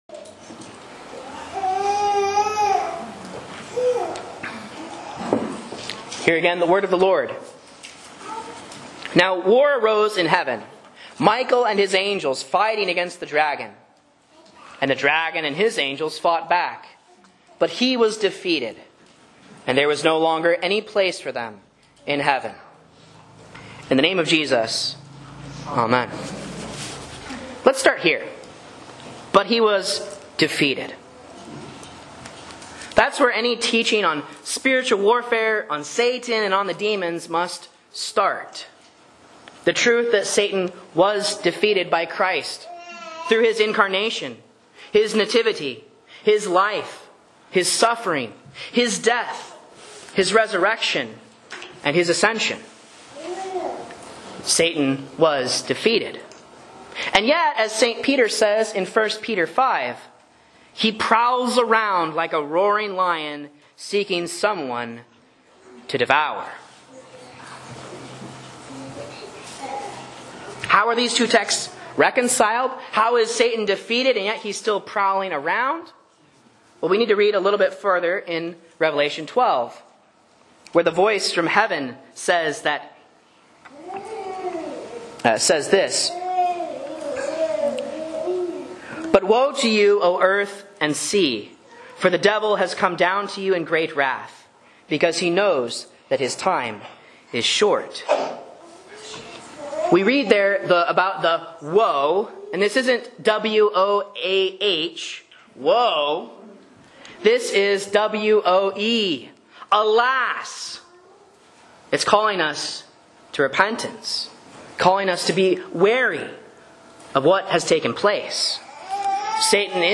Sermons and Lessons from Faith Lutheran Church, Rogue River, OR
A Sermon on Revelation 12:7-12 for St. Michael's Day 2025